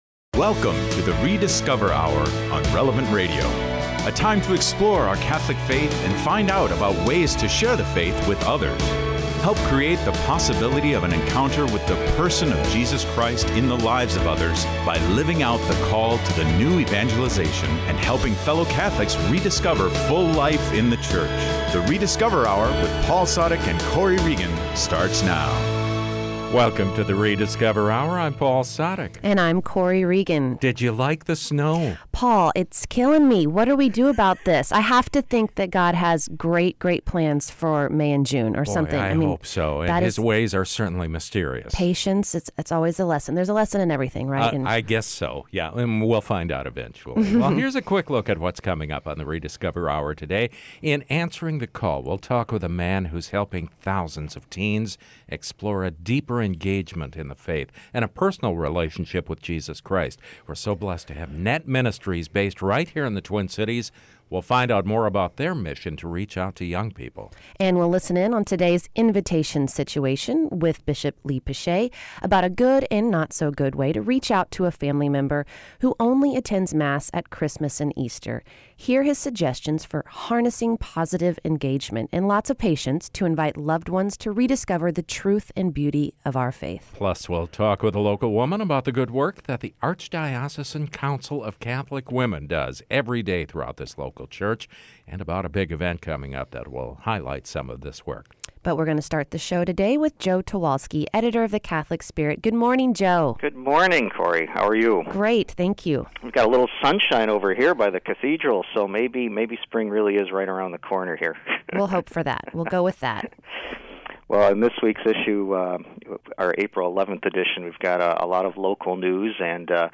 Y en nuestra sección «Situación de invitación», el obispo Lee Piché compartió una forma positiva de acercarse a un familiar o amigo que solo asiste a misa en Pascua y Navidad. Además, escuchamos a católicos locales hablar sobre sus recuerdos de la Confirmación.